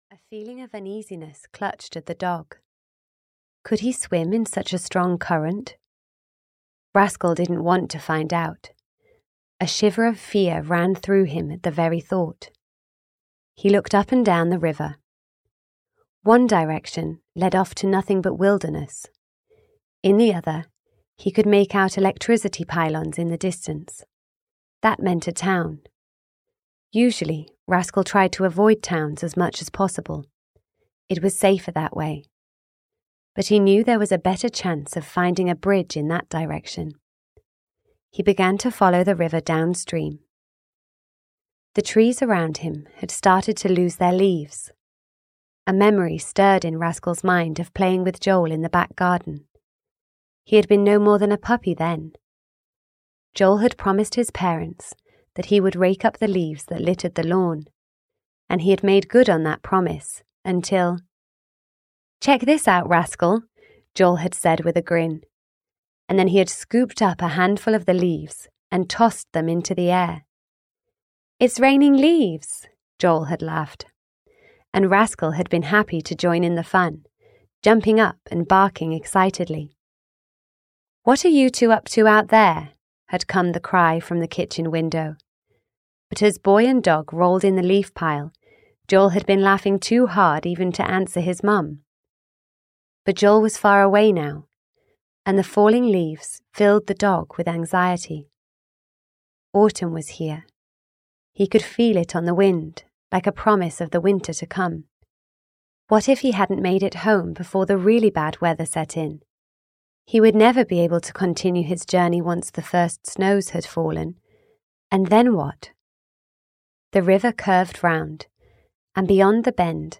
Rascal 5 - Swept Beneath The Waters (EN) audiokniha
Ukázka z knihy